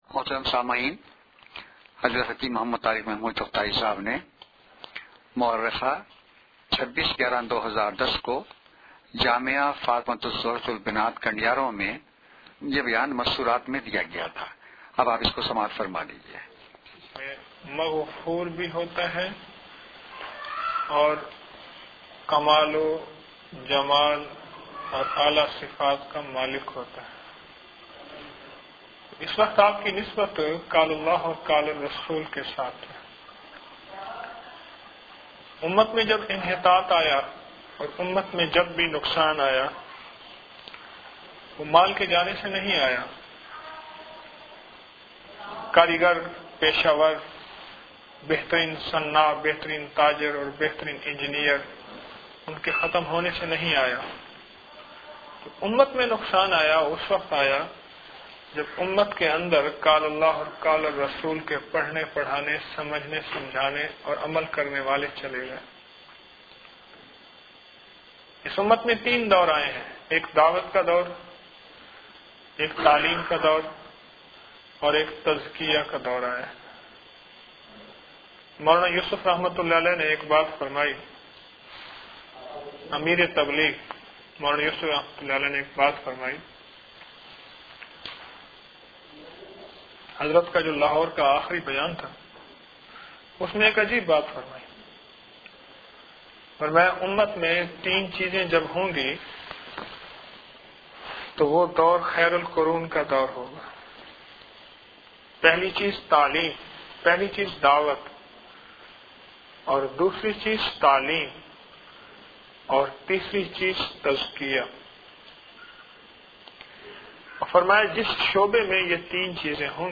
کنڈیارومیں خصوصی بیان - خواتین کے حلقوں میں خصوصی بیان - 26 نومبر 2010ء